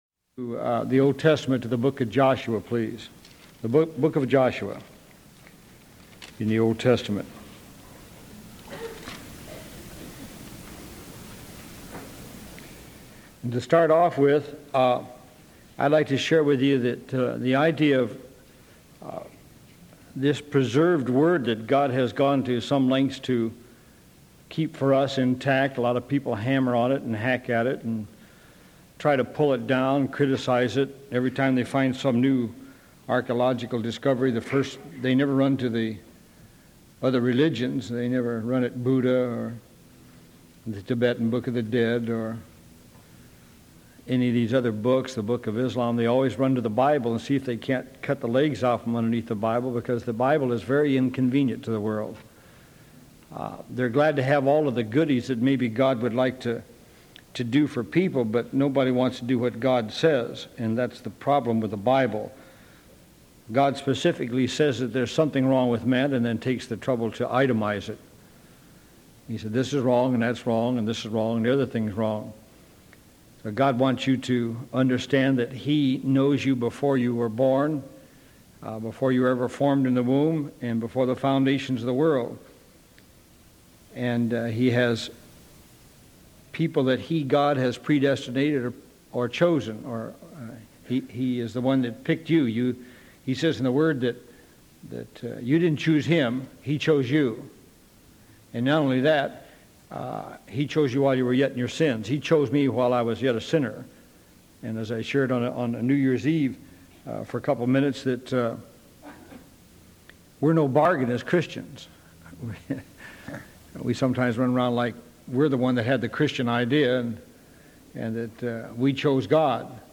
Obedience download sermon mp3 download sermon notes Welcome to Calvary Chapel Knoxville!